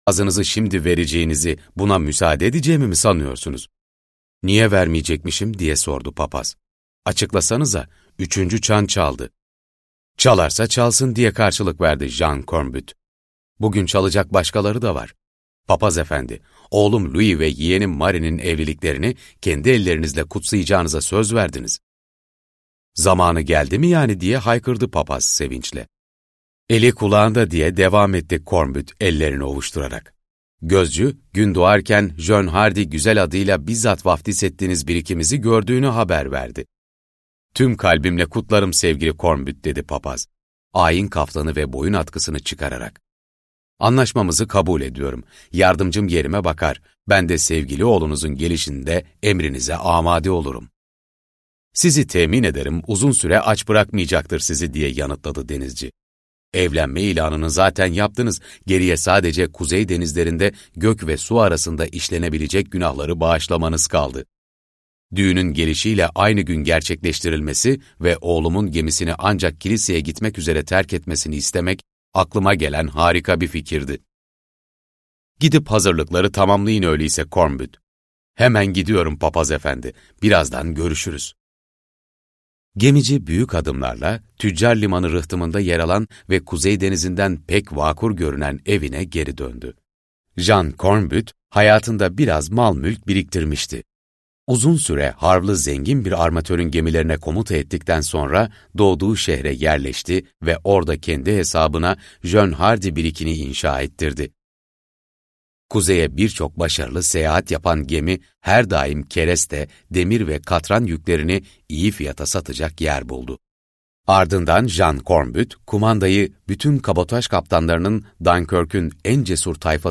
Buzlar Arasında Bir Kış - Seslenen Kitap